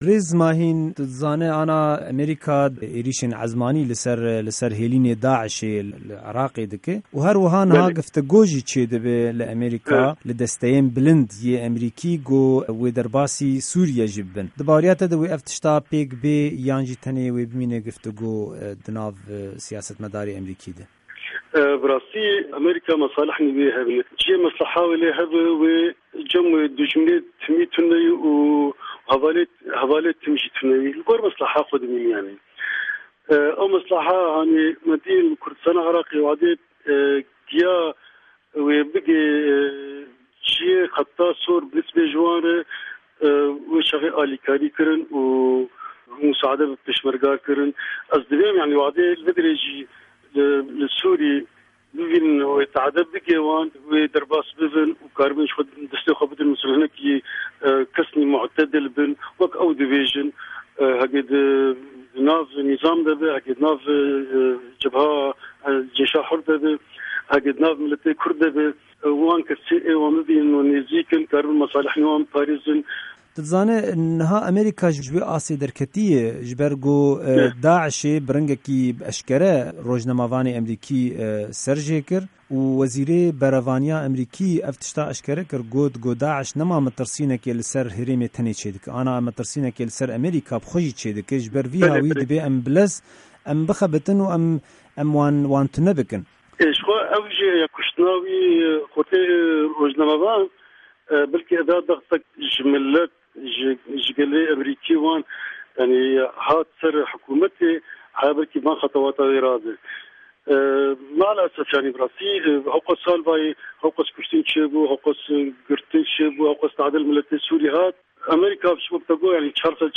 Hevpeyvîna